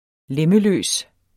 Udtale [ ˈlεməˌløˀs ]